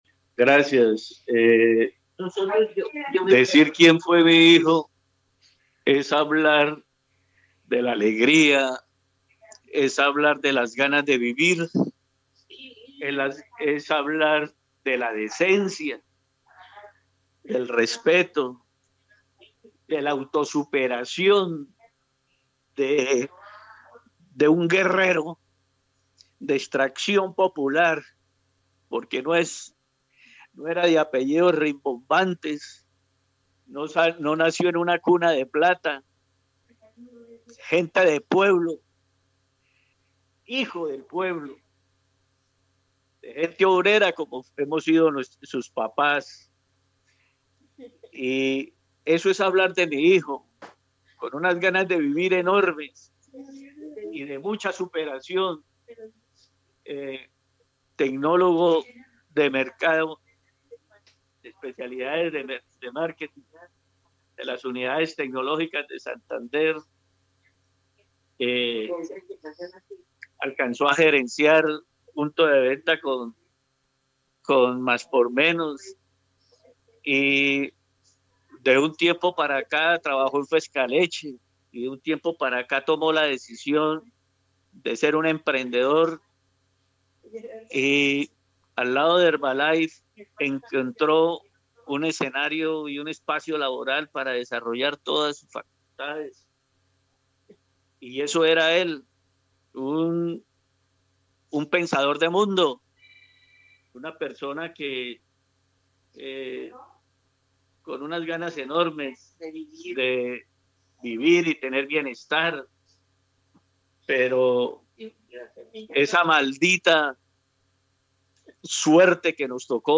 Y es que la voz del ciudadano que perdió a su hijo no tiembla solo de tristeza, tiembla de impotencia.